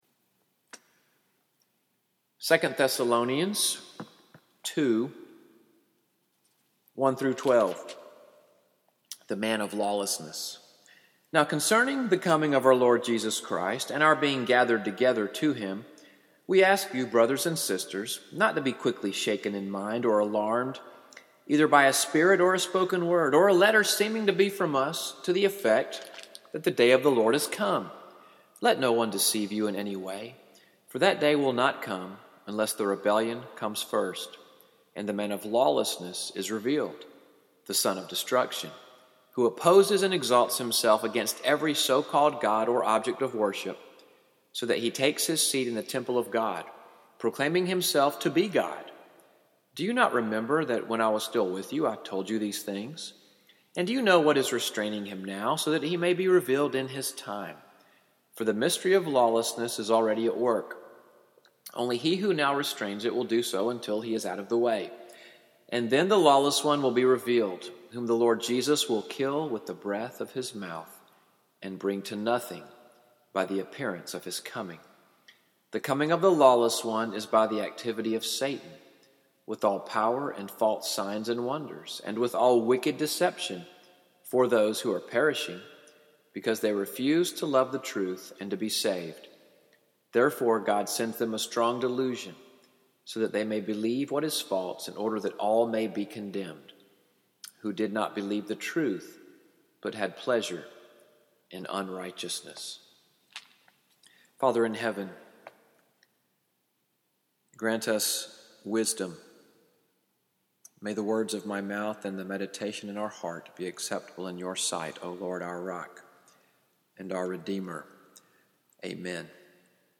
Sermon recorded in the pastor’s study on February 21, 2018.